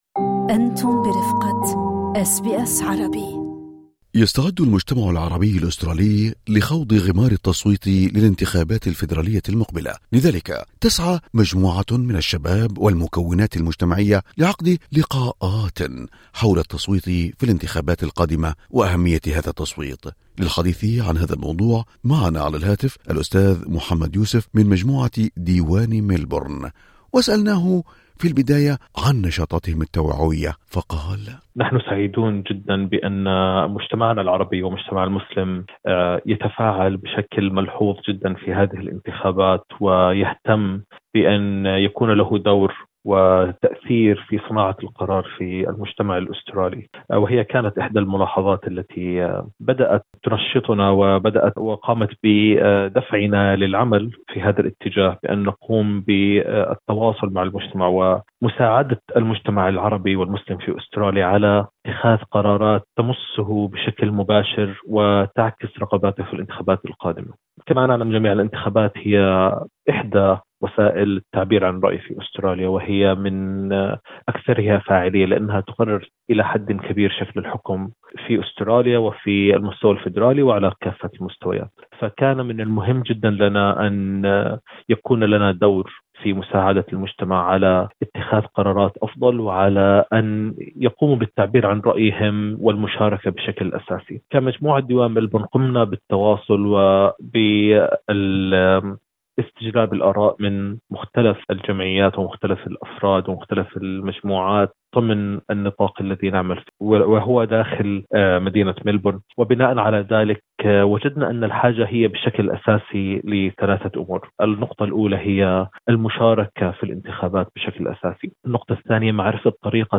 في حديثه مع إذاعة أس بي أس عربي